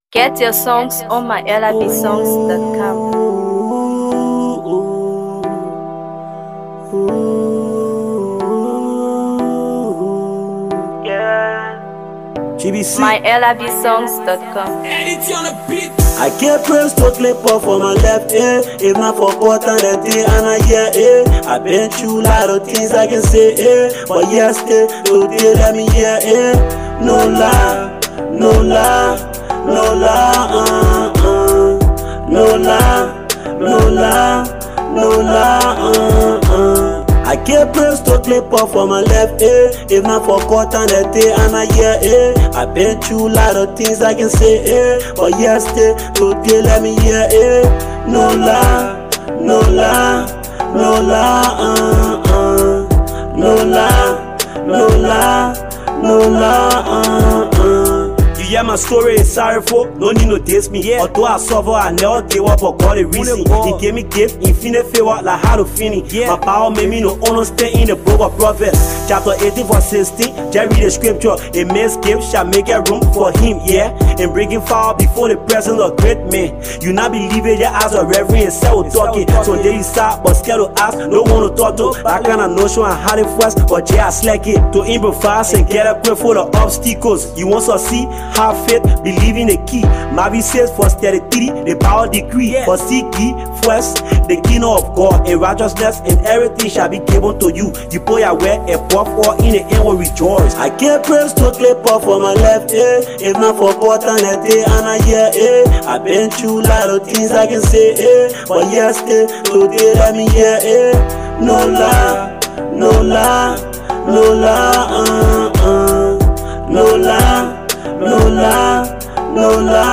Hip HopHipcoMusic
Promising sensational Liberian Hipco rapper